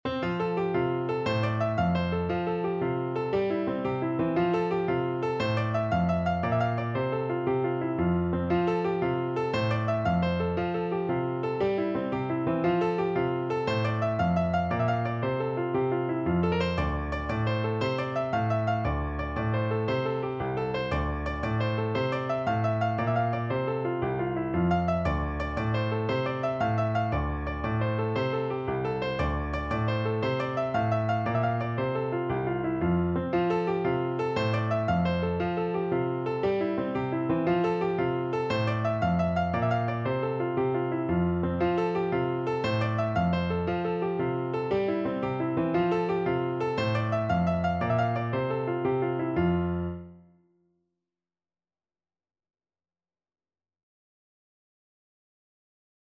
No parts available for this pieces as it is for solo piano.
6/8 (View more 6/8 Music)
With energy .=c.116
Piano  (View more Intermediate Piano Music)
Irish